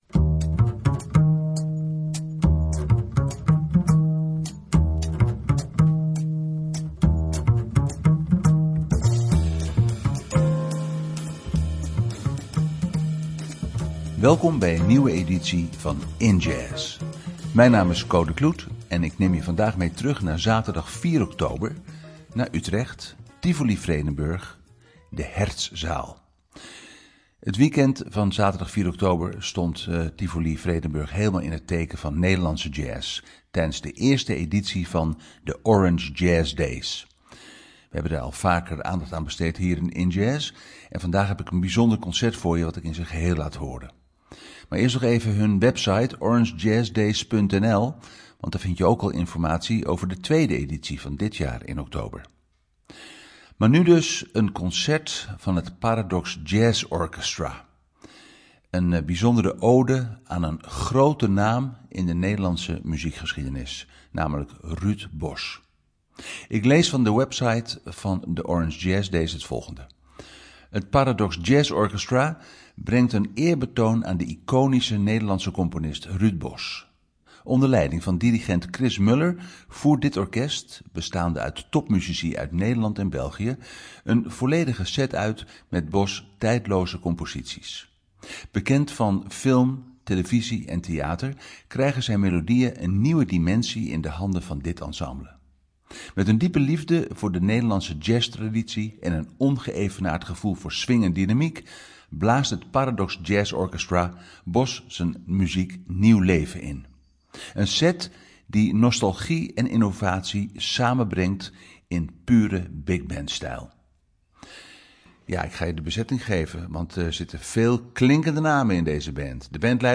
Centraal staat de promotie van jazz en beyond. Nu met het Paradox Jazz Orchestra.